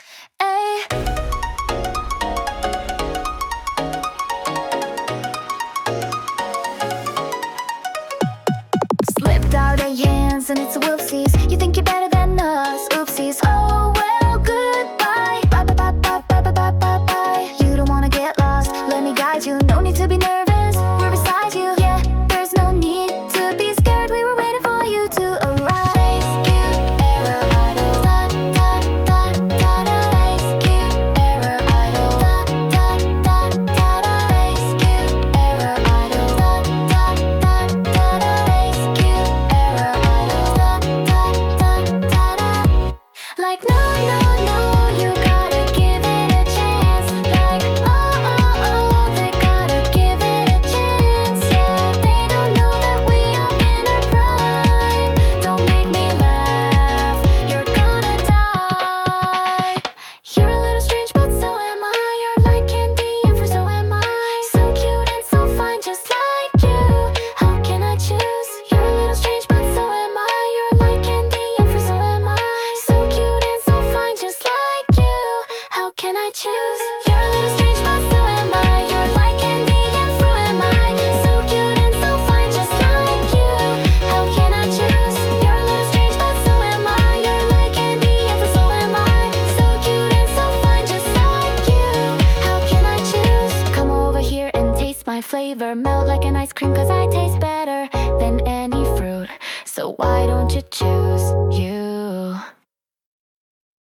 • ジャンル：エラーポップアイドル
• 声：柔らかく、落ち着いたトーン／ささやくような優しさ